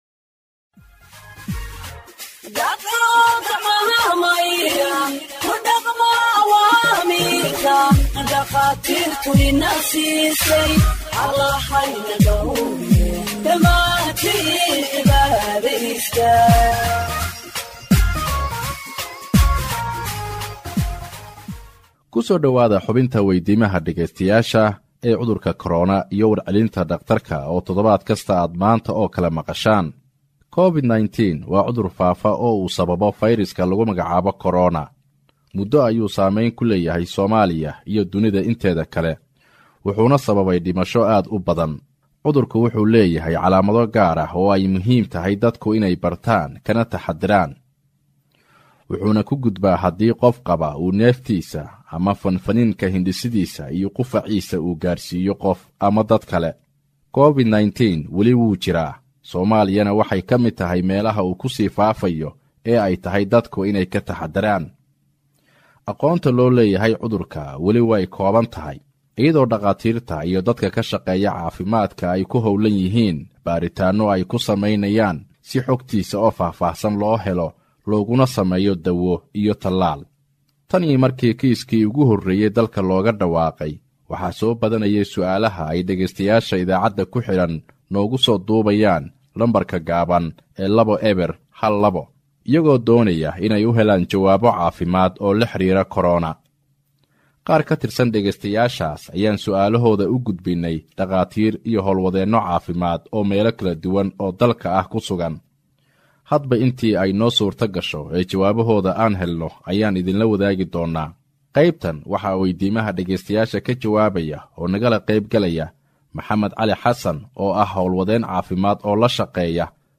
HEALTH EXPERT ANSWERS LISTENERS’ QUESTIONS ON COVID 19 (73)